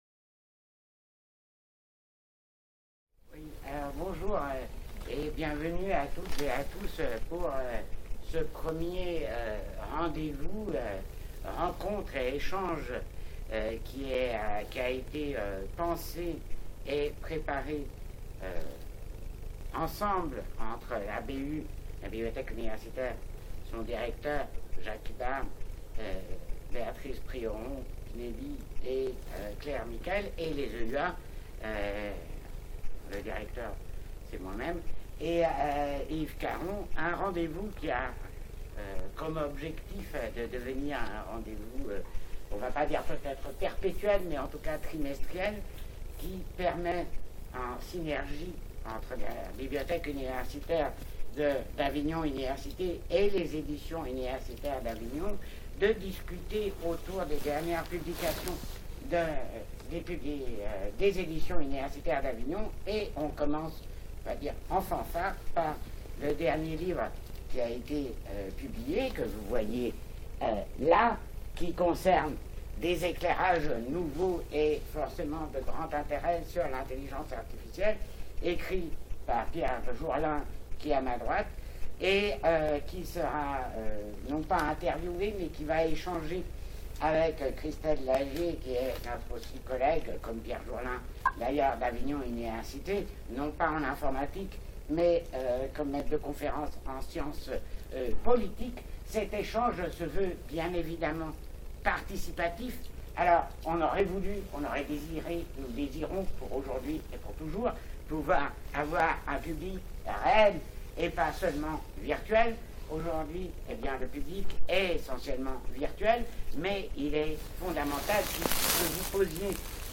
CONFERENCE La boîte translucide